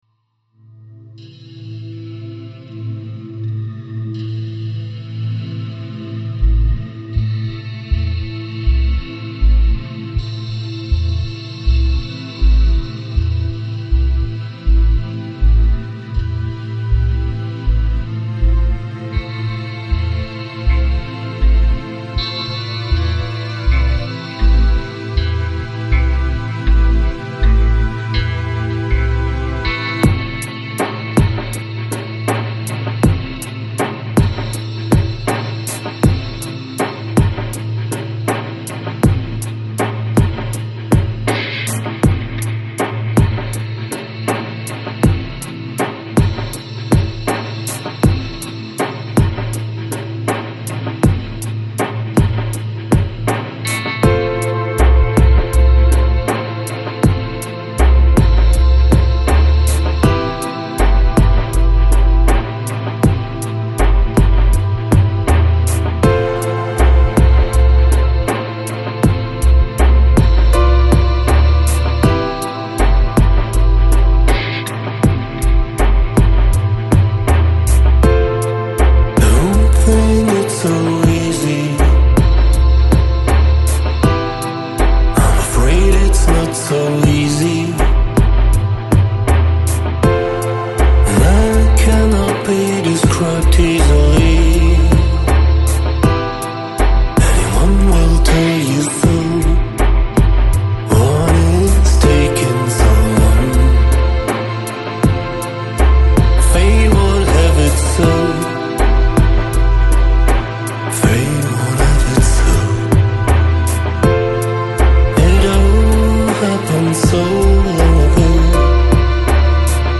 Electronic, Lounge, Chill Out, Downtempo